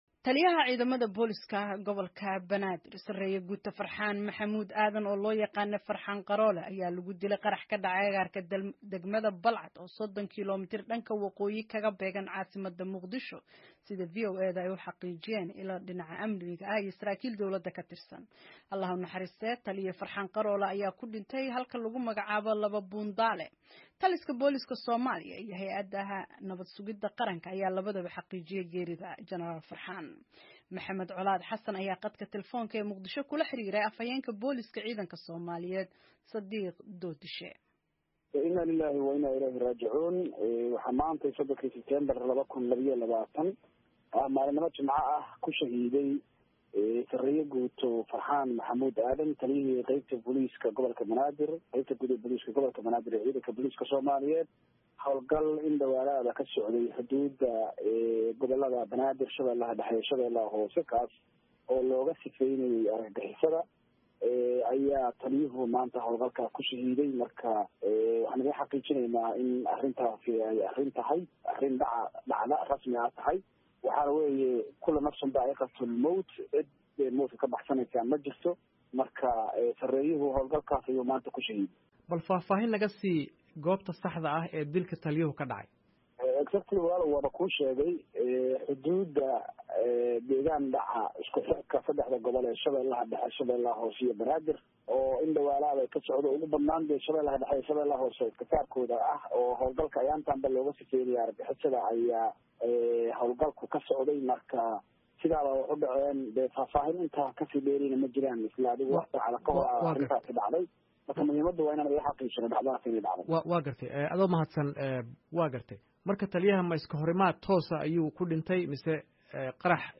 khadka Taleefanka